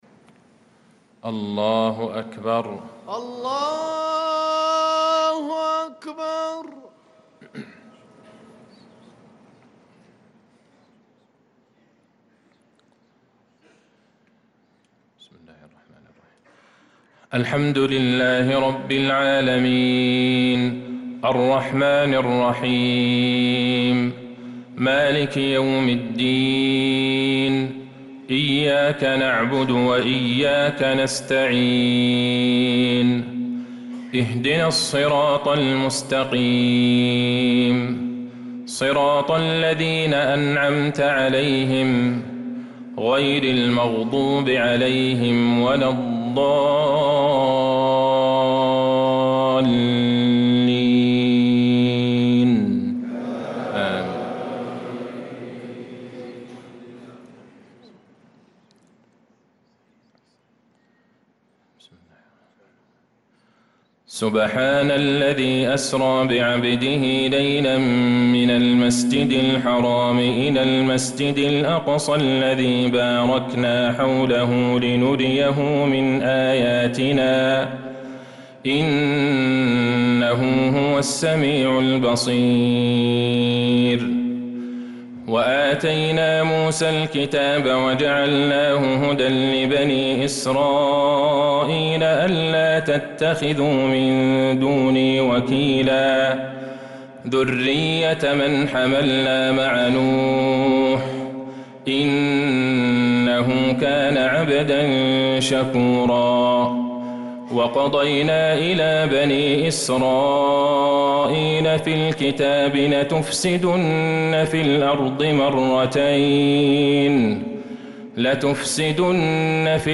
صلاة العشاء للقارئ عبدالله البعيجان 17 ربيع الآخر 1446 هـ
تِلَاوَات الْحَرَمَيْن .